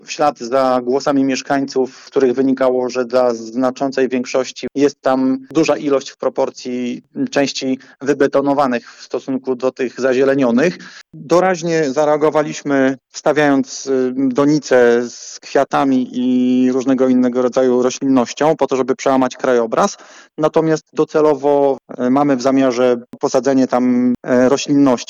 Burmistrz Krzysztof Sypień podkreśla, że to odpowiedź na głosy mieszkańców.